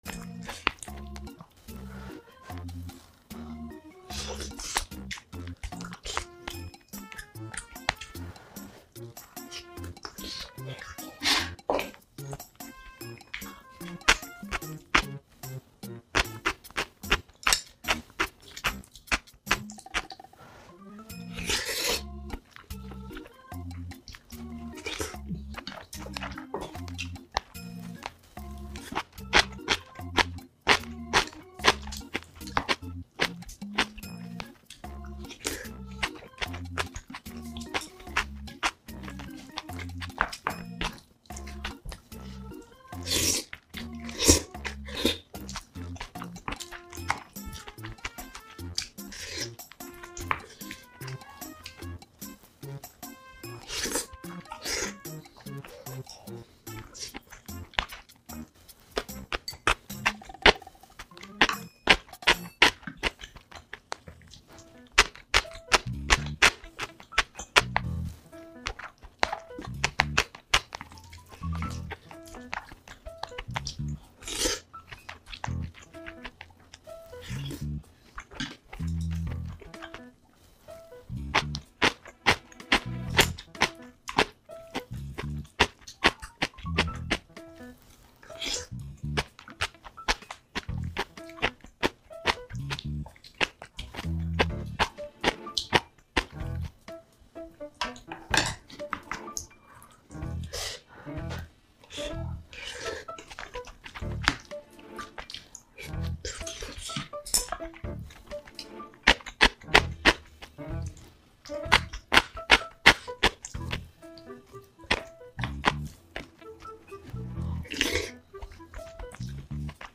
Korean Mukbang